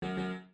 For the 'No', I used a low, double note on the dulcimer. The 'Yes' chime is in A major, and the 'No' plays an F#, which is the tonic of the relative minor.
Audio for the 'No' sound
error.mp3